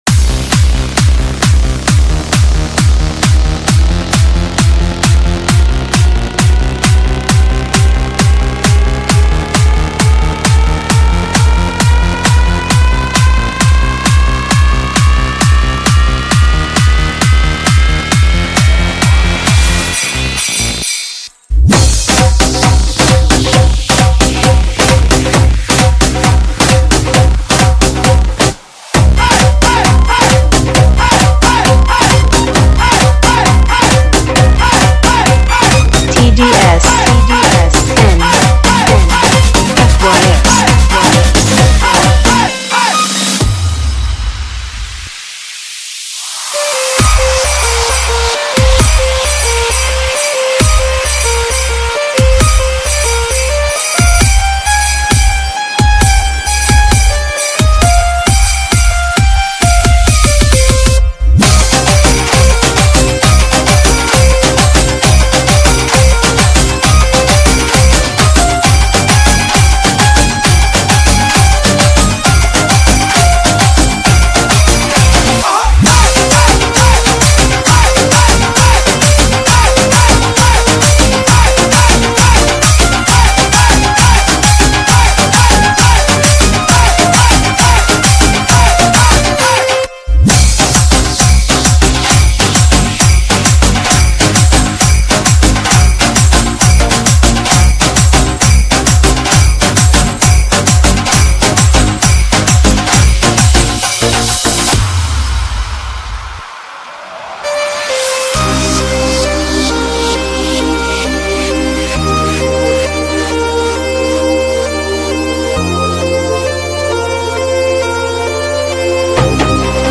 Category : Festival Remix Song